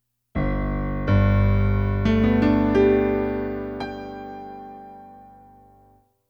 piano1.wav